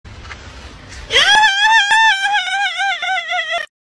Reactions
Goofy Ahh Laugh Meme Sound